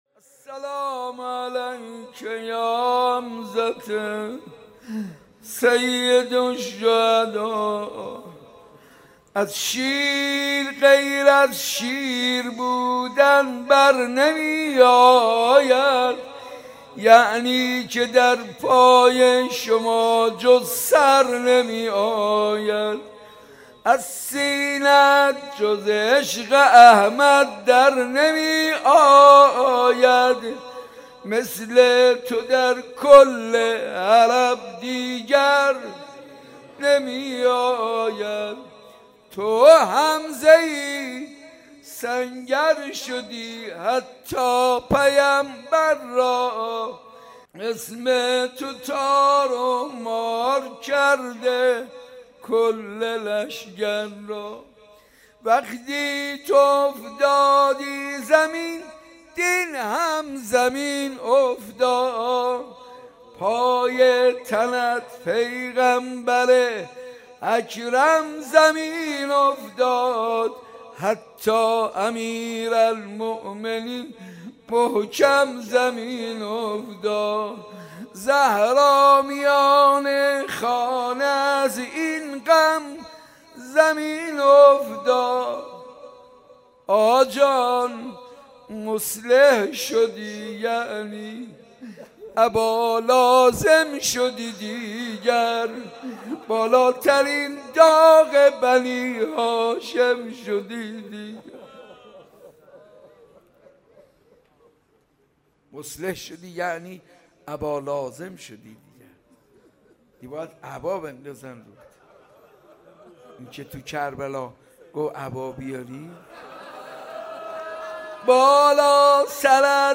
شهادت حضرت حمزه و گریز روضه ی کربلا